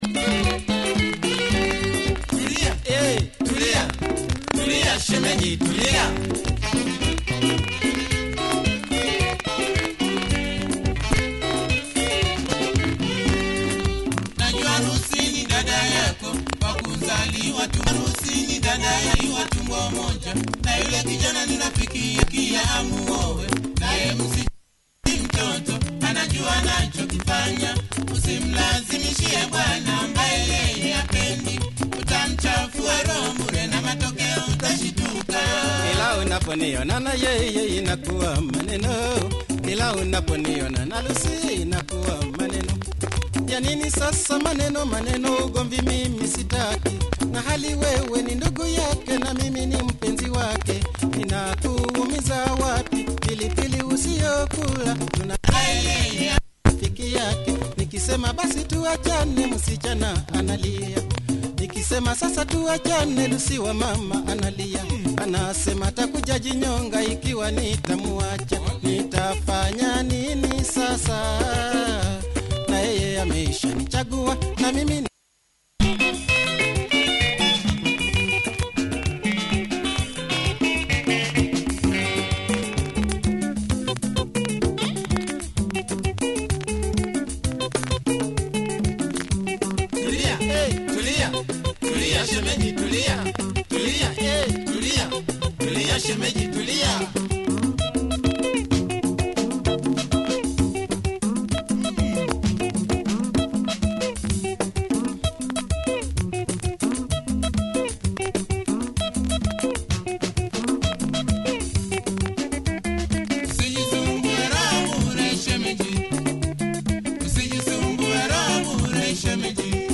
Rare Tanzania track, has marks that affect play!